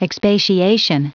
Prononciation du mot expatiation en anglais (fichier audio)
expatiation.wav